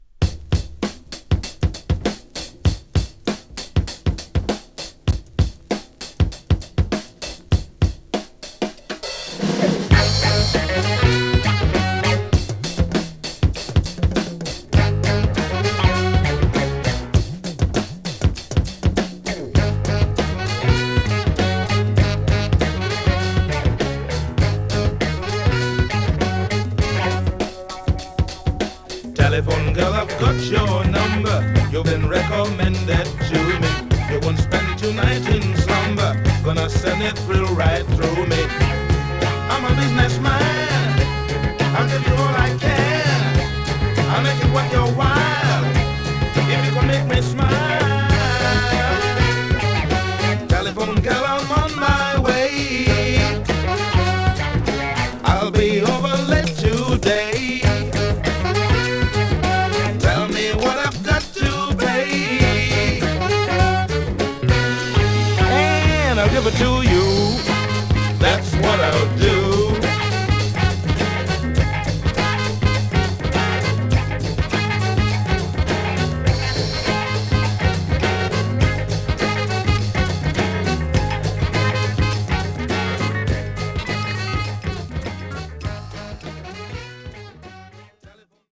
どの曲も乾いたサウンドの切れ味バツグンなドラムブレイクばかりを収録。